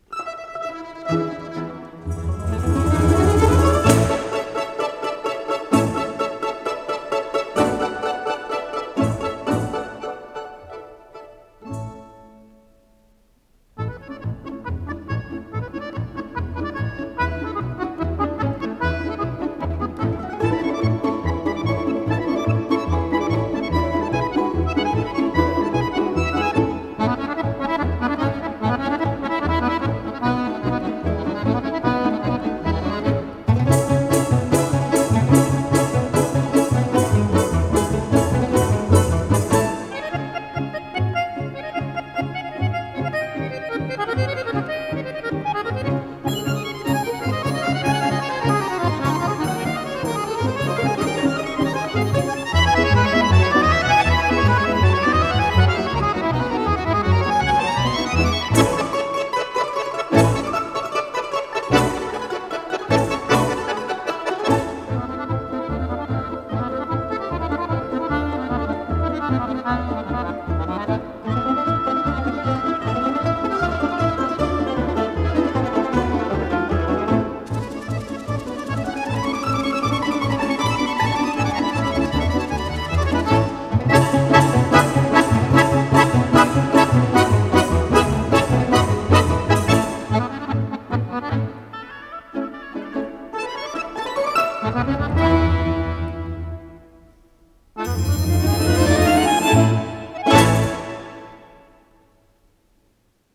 с профессиональной магнитной ленты
АккомпаниментАнсамбль русских народных инструментов